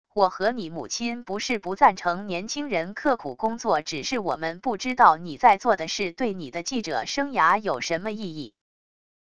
我和你母亲不是不赞成年轻人刻苦工作――只是我们不知道你在做的事对你的记者生涯有什么意义wav音频生成系统WAV Audio Player